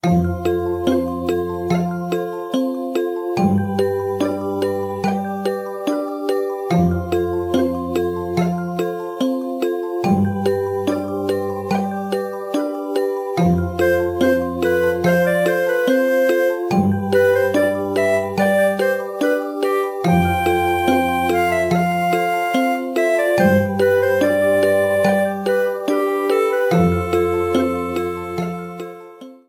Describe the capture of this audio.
Ripped from the remake's files trimmed to 29.5 seconds and faded out the last two seconds